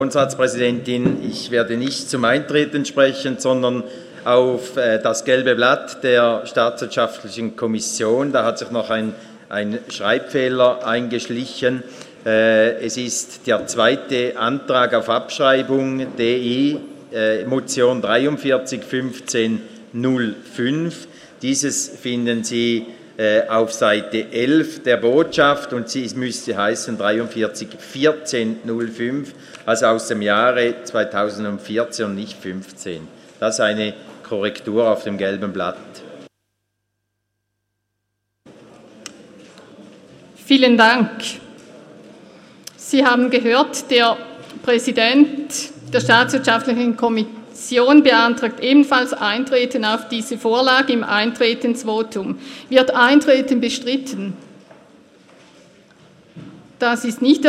Session des Kantonsrates vom 11. bis 13. Juni 2018